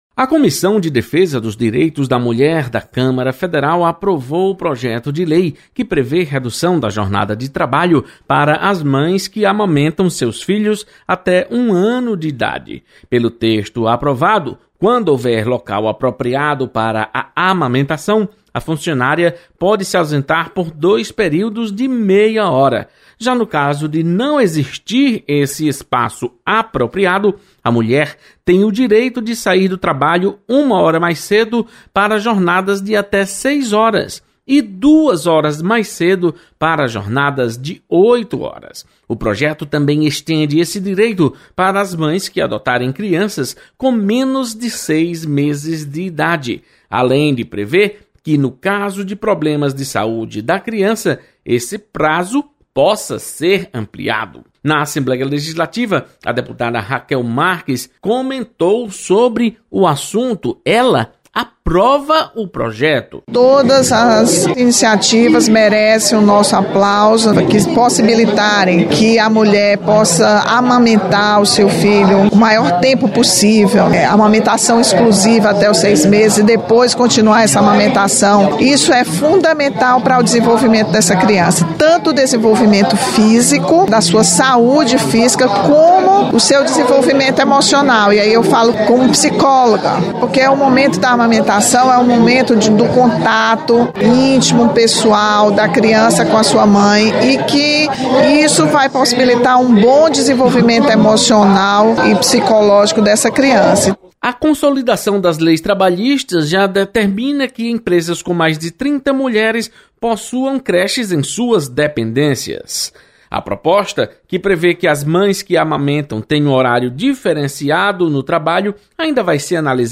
Redução da jornada de trabalho para mães que amamentam é aprovada em comissão na Câmara Federal. Repórter